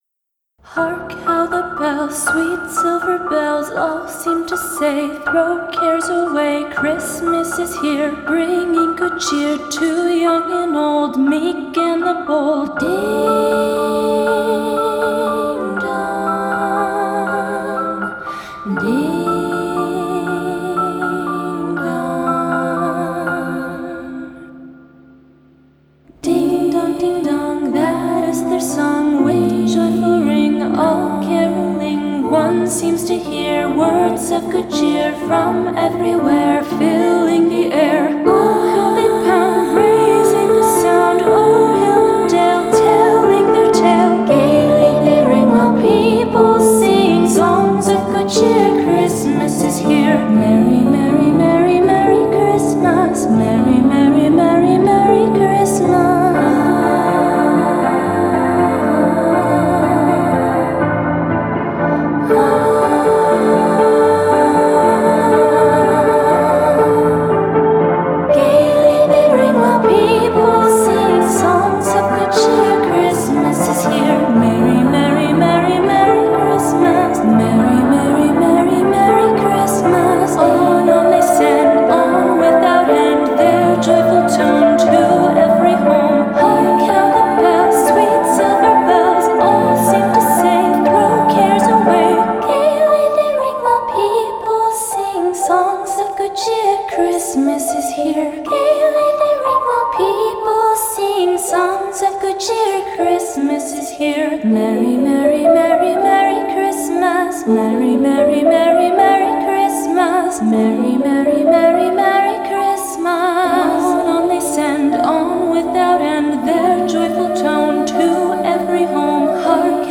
Vocal Piano Version